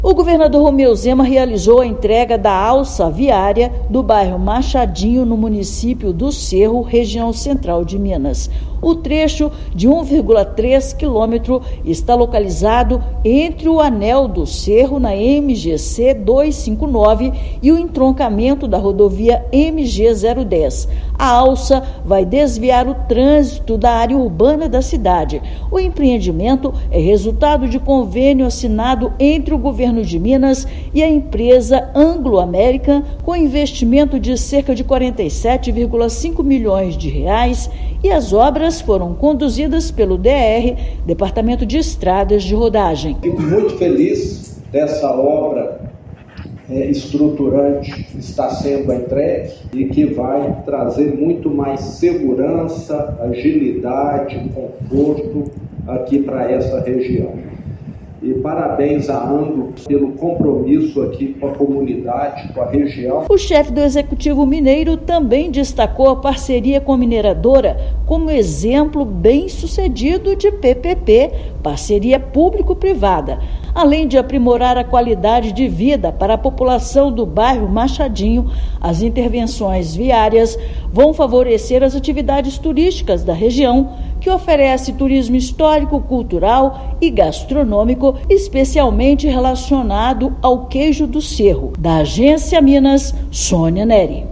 Trecho vai reduzir o trânsito de dentro da área urbana do município, garantindo mais segurança a moradores e visitantes. Ouça matéria de rádio.